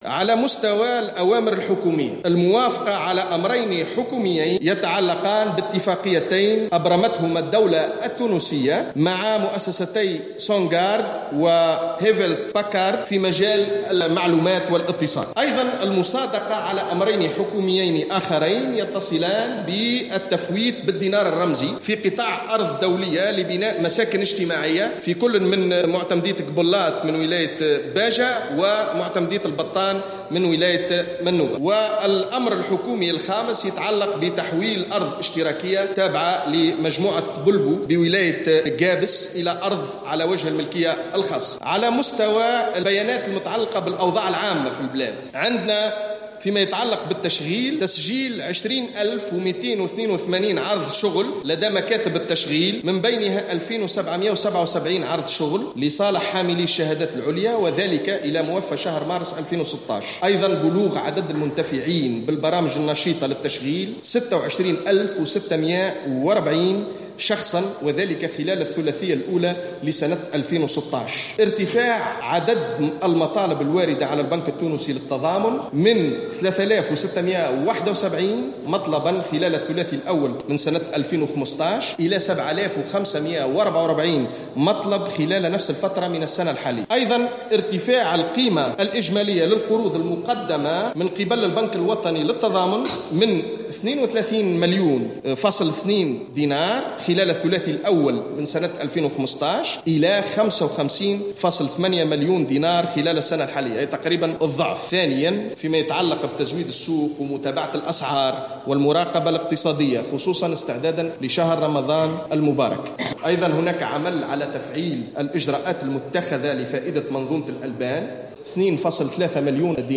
أكد الوزير، الناطق الرسمي باسم الحكومة، خالد شوكات، أن الدولة ماضية في تطبيق القانون وإعادة العمل في مواطن الثروة بكافة الوسائل التي يتيحها لها القانون، وذلك في تصريح إعلامي عقب اجتماع مجلس الوزراء مساء اليوم الاربعاء، في دار الضيافة بقرطاج.